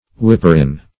Search Result for " whipperin" : The Collaborative International Dictionary of English v.0.48: Whipperin \Whip"per*in`\, n. [1913 Webster] 1. A huntsman who keeps the hounds from wandering, and whips them in, if necessary, to the of chase.